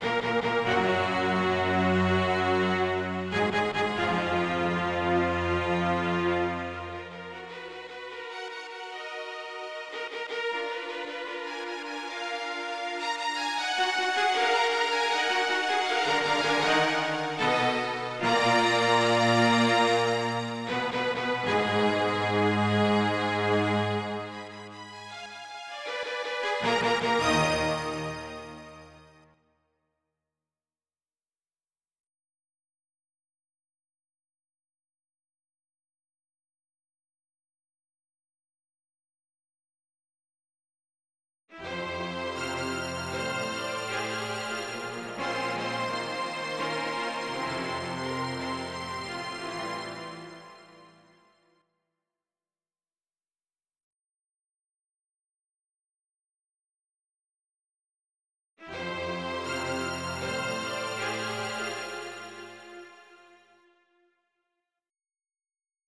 MIDI Music File
Type General MIDI